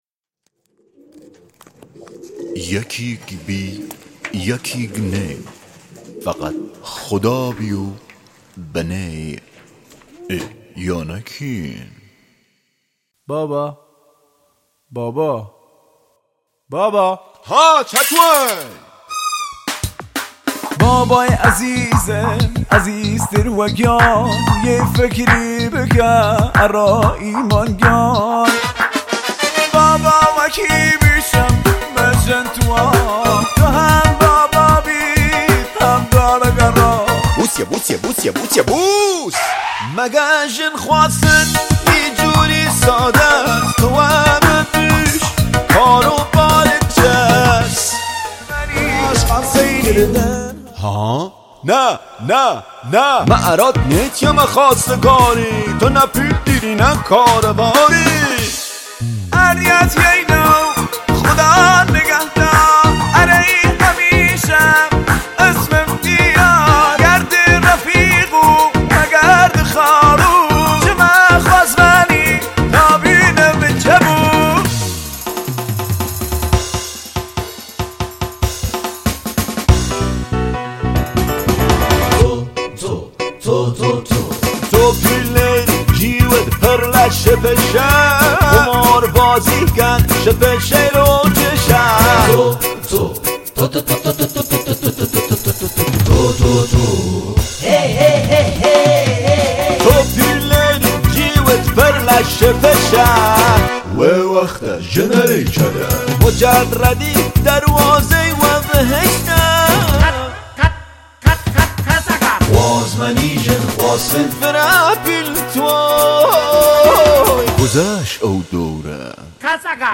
Remix Music